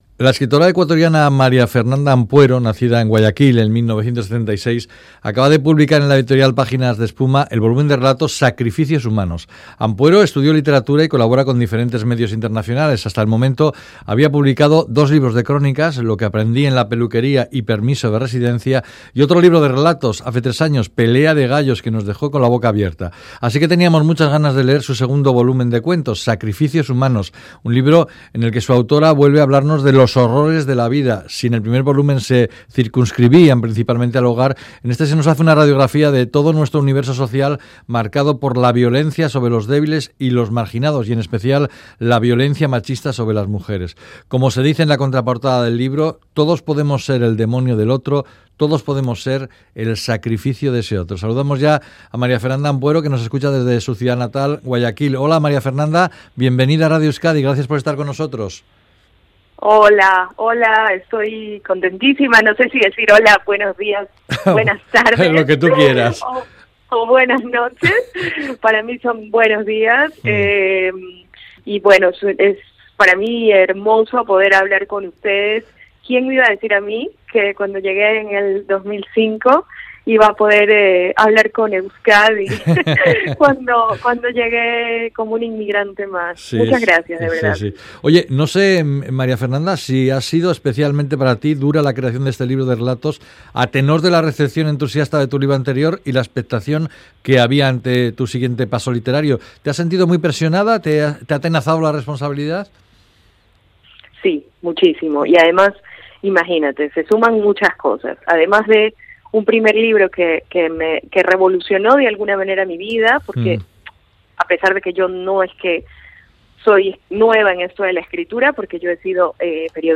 Audio: Charlamos con la ecuatoriana María Fernanda Ampuero sobre su libro de relatos "Sacrificios humanos", un grito contra de la violencia sobre los más débiles